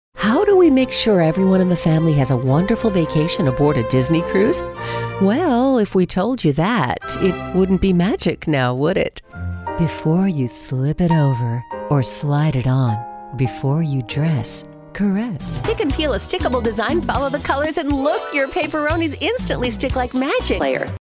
Warm, melodic, rich, and resonant - from sparking to sexy.
englisch (us)
Sprechprobe: Werbung (Muttersprache):